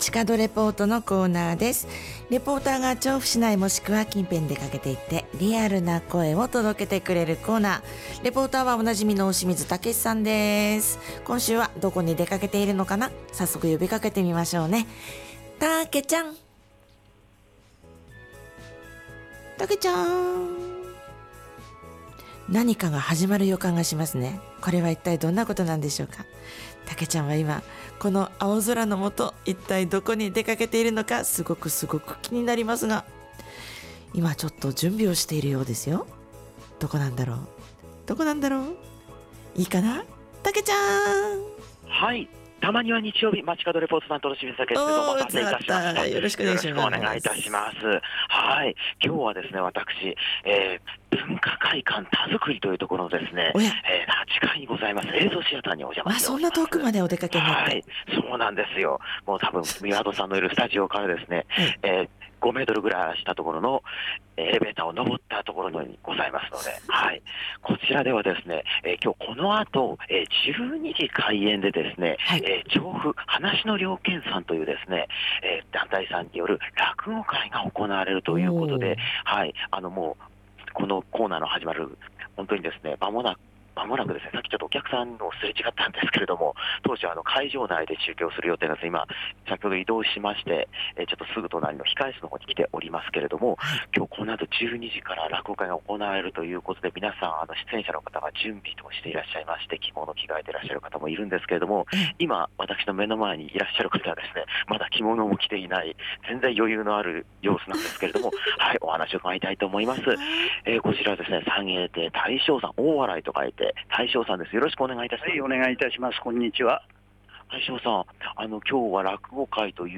秋晴れの空の下から、お届けした本日の街角レポートは、文化会館たづくりで行われる『ちょうふ・噺の了見』さんからのレポートです！！